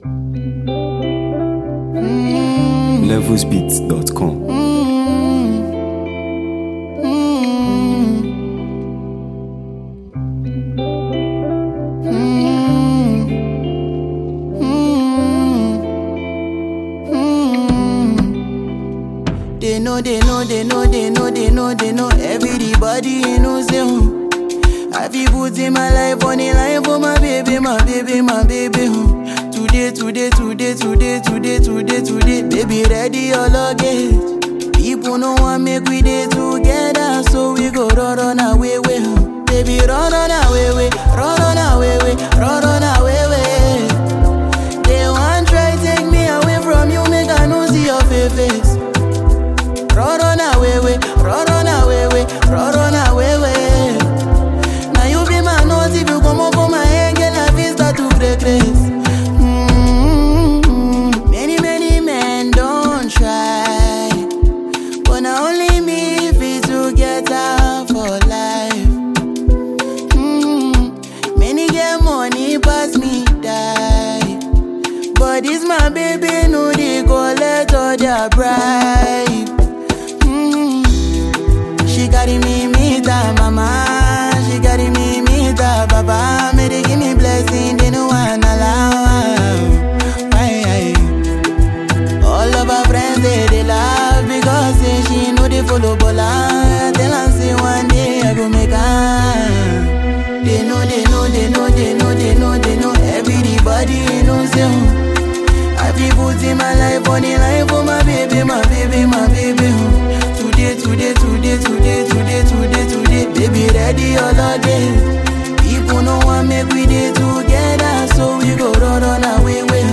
heartfelt and melodious track
smooth vocals